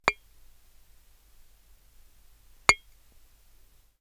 Recording of a brick being struck in an anechoic chamber
Anechoic.aiff